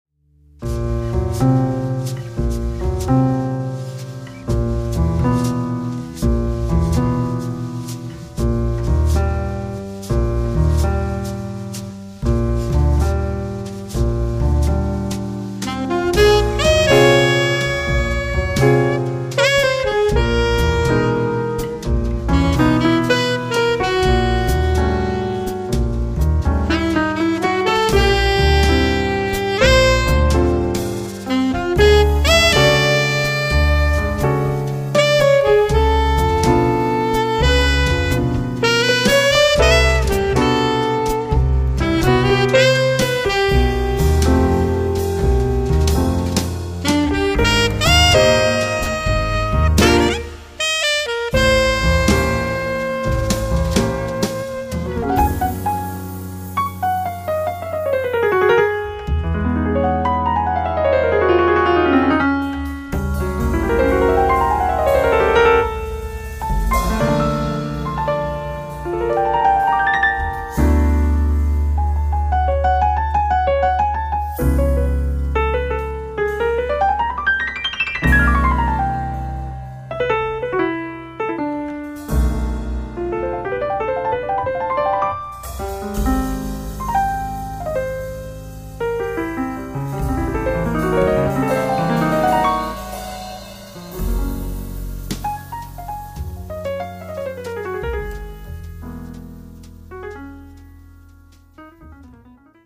sax alto
piano, piano elettrico
contrabbasso, basso elettrico
batteria